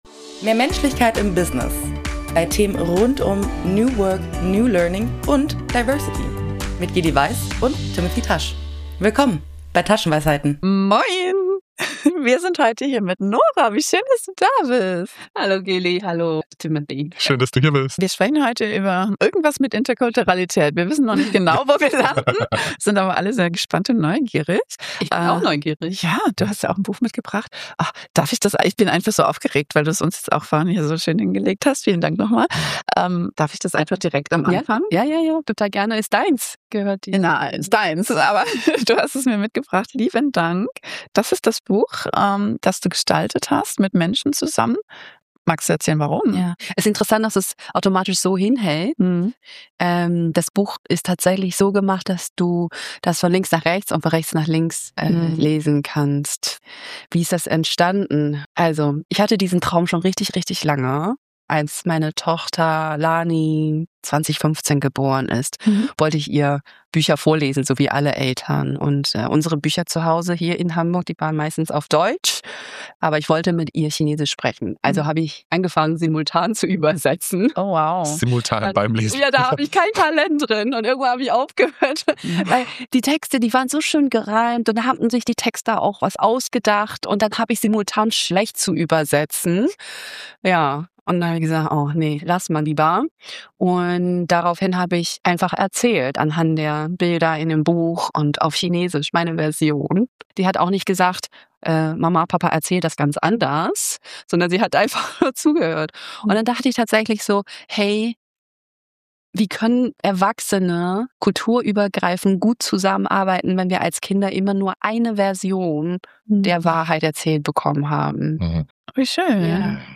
Was als Gespräch über ein Kinderbuch beginnt, wird schnell zu einem offenen, vielschichtigen Austausch über Prägung, Schubladen, Intersektionalität, kulturelle Codes und die Frage, was es braucht, damit unterschiedliche Versionen der Wahrheit nebeneinander Platz haben. Im spielerischen Element dieser Folge erkunden wir das Thema mit Aufgabenzetteln und erleben live, was passiert, wenn unterschiedliche Prägungen und Erwartungen aufeinandertreffen und was unsere Interpretation mit unserem Verhalten macht.